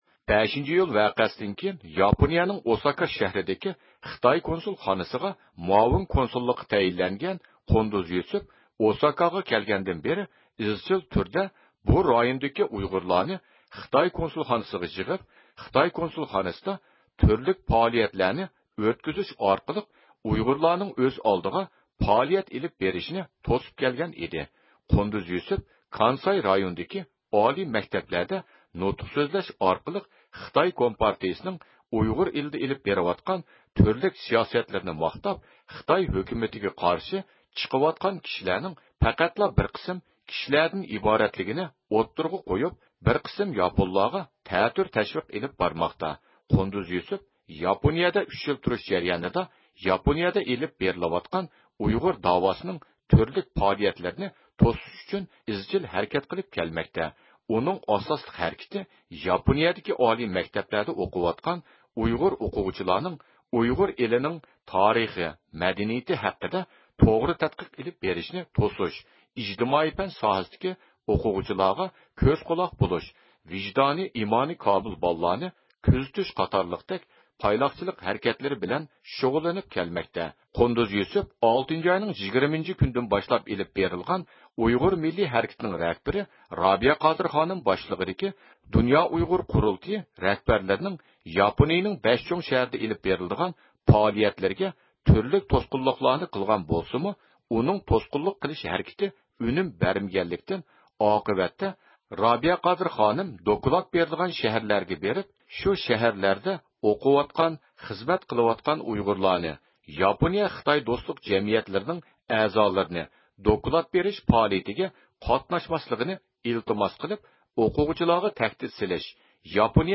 بىز بۇ مۇناسىۋەت بىلەن رابىيە قادىر خانىمنى زىيارەت قىلىپ، بۇ خىل ئىنكاس توغرىسىدا بىر قىسىم ئۇچۇرلارغا ئىگە بولدۇق.